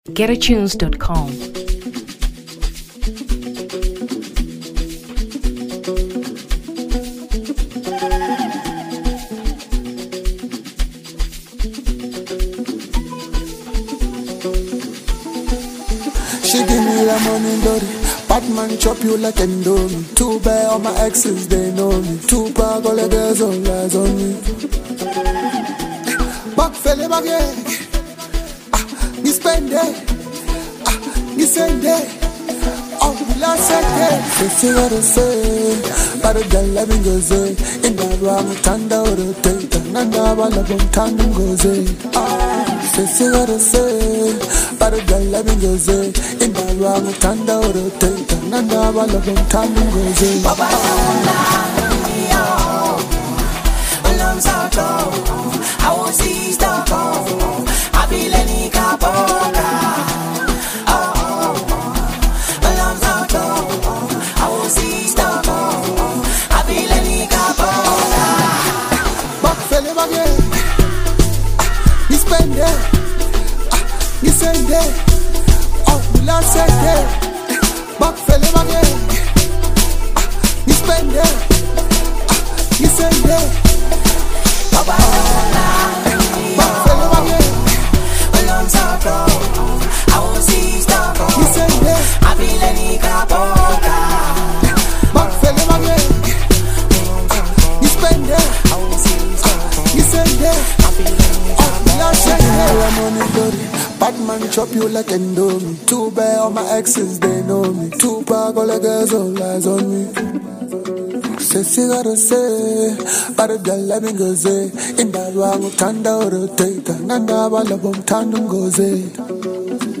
Amapiano 2023 South Africa